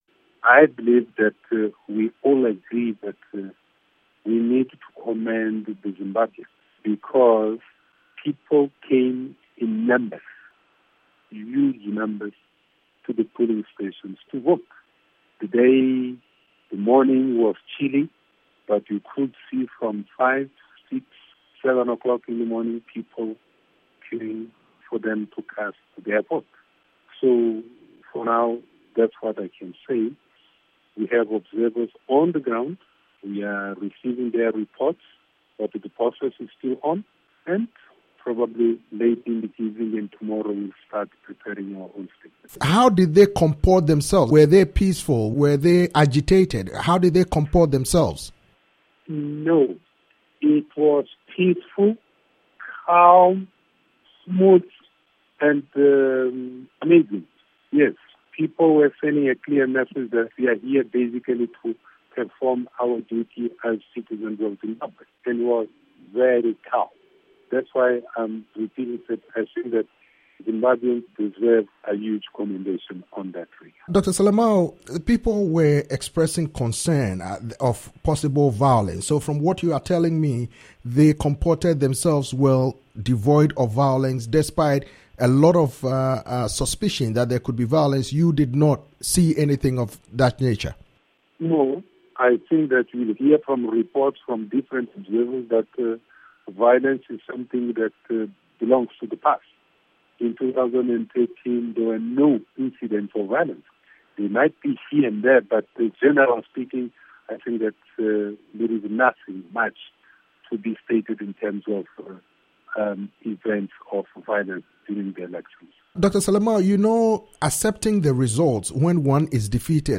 interview with Dr.Tomaz Salomão SADC's executive secretary